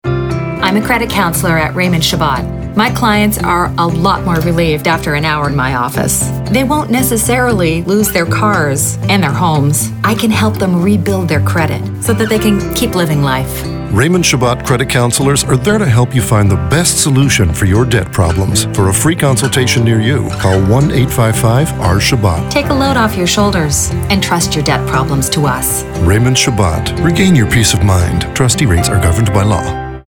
Pour Raymond Chabot, leader de cette industrie au Québec, nous avons plutôt adoptés un ton calme, respectueux et qui invite à la confidence.
Radios